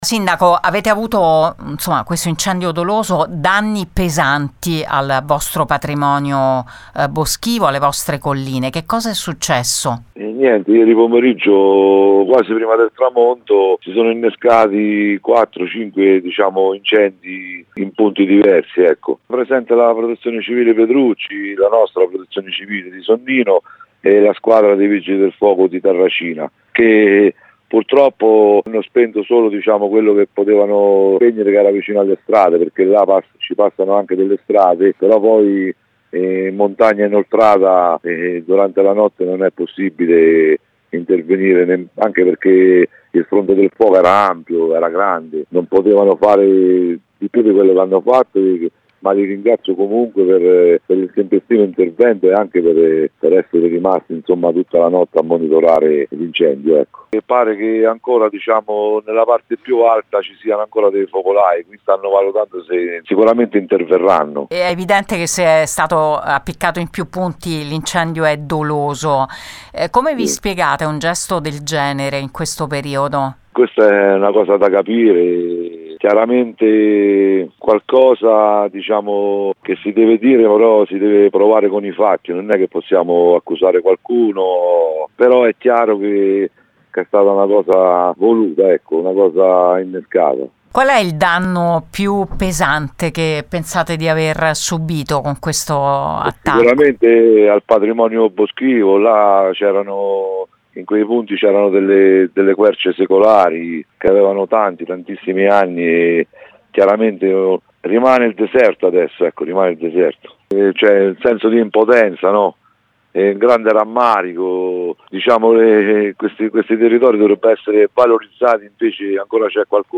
Il sindaco di Sonnino Gianni Carroccia racconta quanto accaduto e il grande senso di impotenza e di rammarico di fronte alla distruzione di un patrimonio di tutti: “E’ rimasto il deserto assoluto. Non resteremo inerti, abbiamo il dovere di individuare i responsabili. Abbiamo qualche elemento, ma vogliamo appurare meglio prima di parlare”.
sindaco-Sonnino-Caroccia.mp3